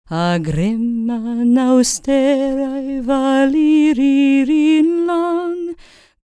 L CELTIC A15.wav